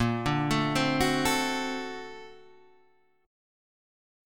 Bb+M9 chord